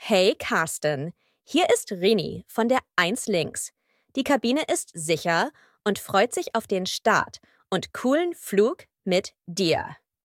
CallCabinSecureTakeoff.ogg